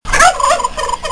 Le dindon | Université populaire de la biosphère
il glougloute
dindon.mp3